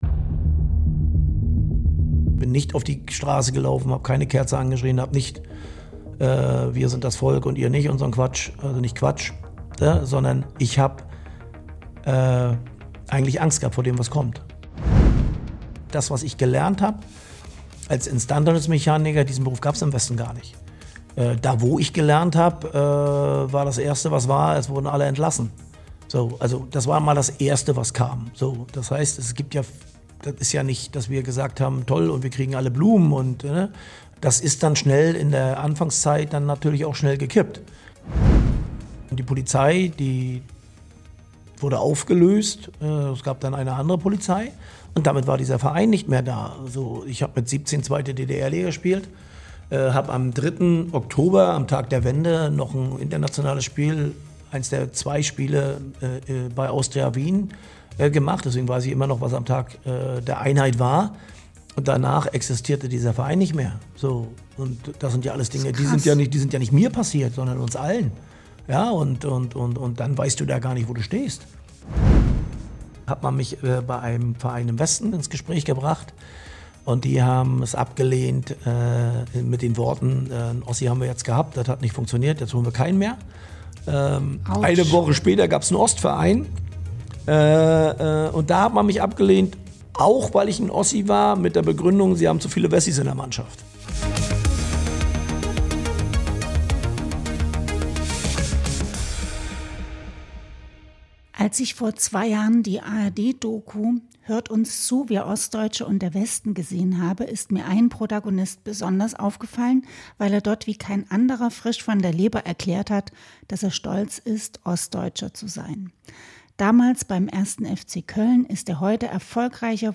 1. FC Union Berlin-Trainer Steffen Baumgart im Gespräch